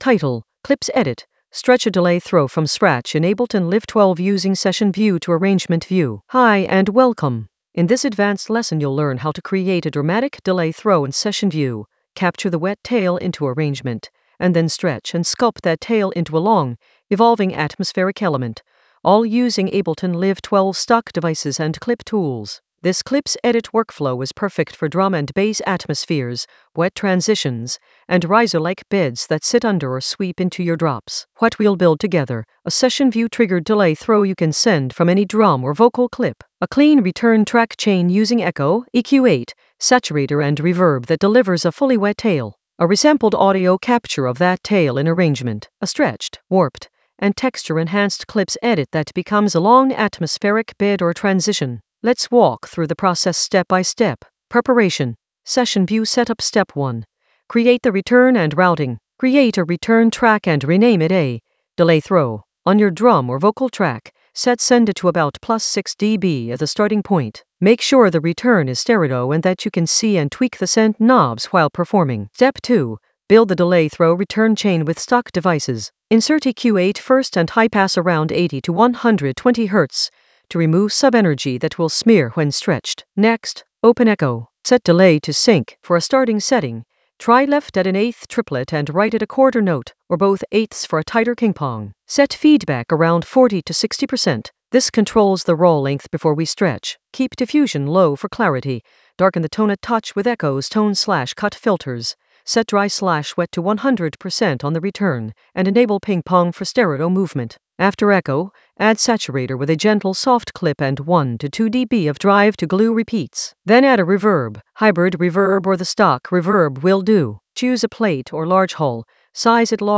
An AI-generated advanced Ableton lesson focused on Clipz edit: stretch a delay throw from scratch in Ableton Live 12 using Session View to Arrangement View in the Atmospheres area of drum and bass production.
Narrated lesson audio
The voice track includes the tutorial plus extra teacher commentary.